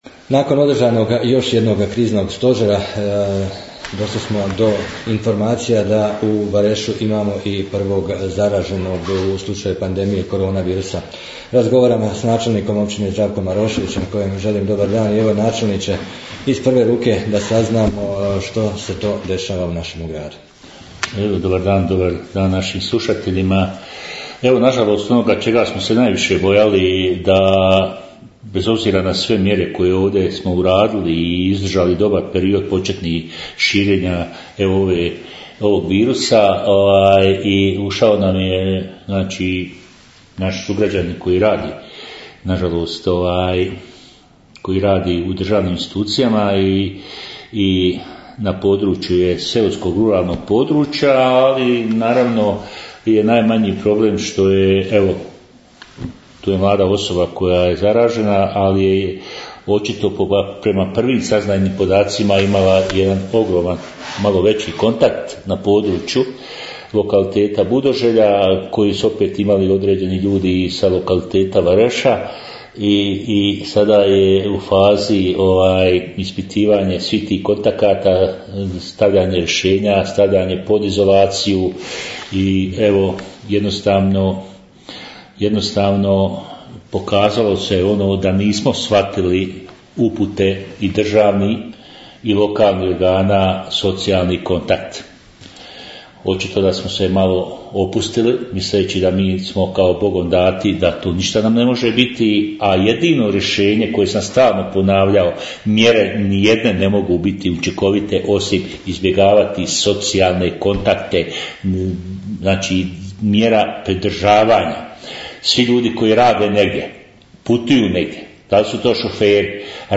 Nakon pojave koronavirusa u Varešu razgovarali smo s načelnikom Zdravkom Maroševićem o poduzetim aktivnostima i općem stanju u našoj lokalnoj zajednici, poslušajte...